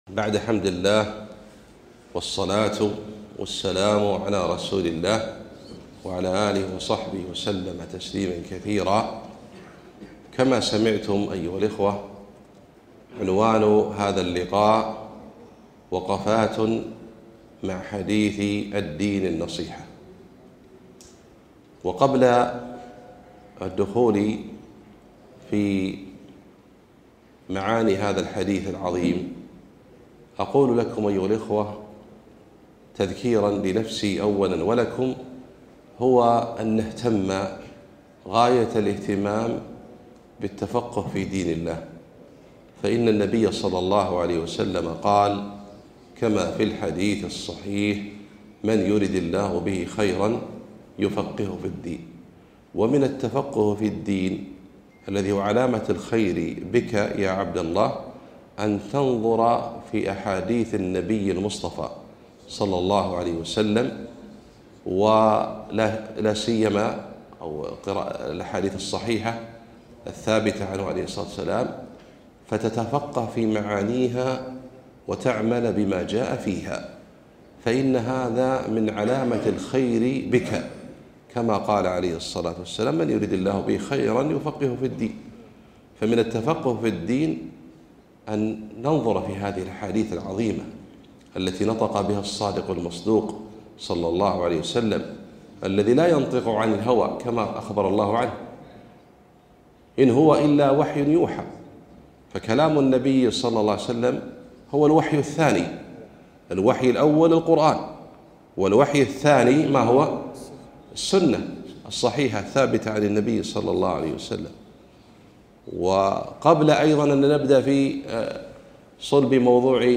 محاضرة - وقفات مع حديث الدين النصيحة